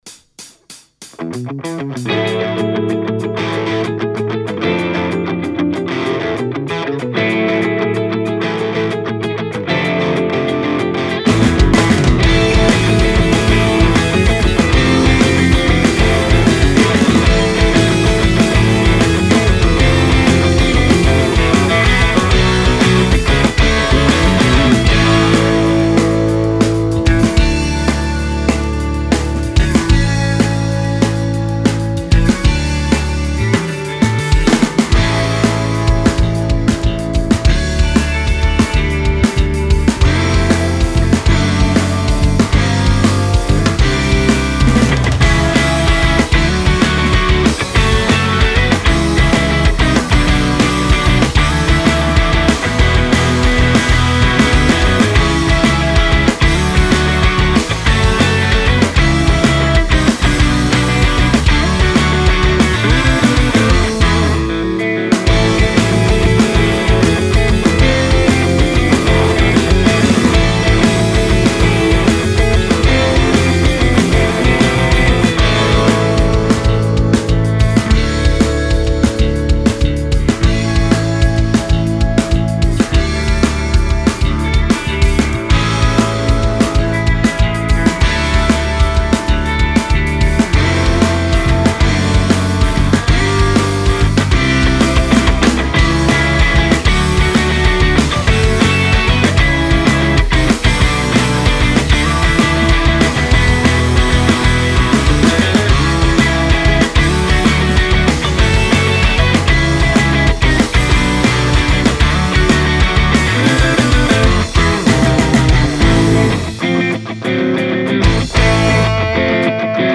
Всё на Neural DSP PLINI гитары.Не "тяжмет".
Простенький инструментальчик,уж больно мне PLINI нравится, вот и вцепился в него. Все гитары в него,в различных комбинациях хоть их не так много.